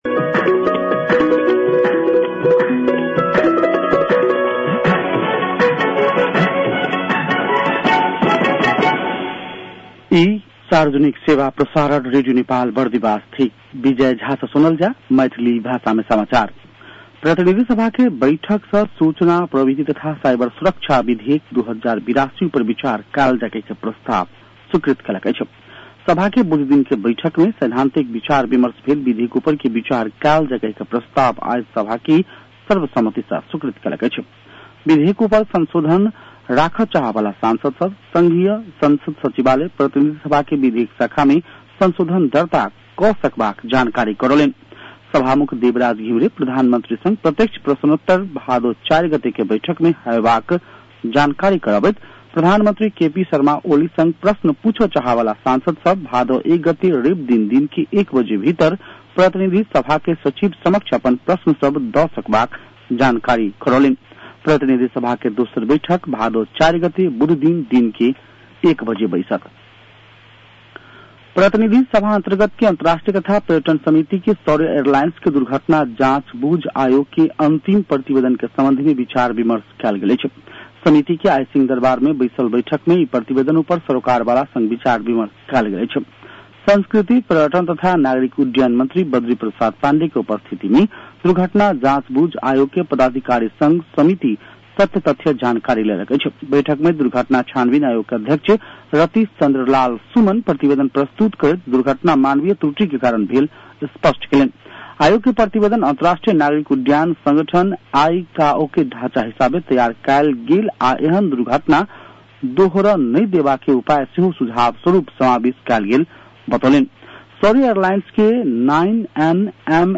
मैथिली भाषामा समाचार : २९ साउन , २०८२
6.-pm-maithali-news-1-2.mp3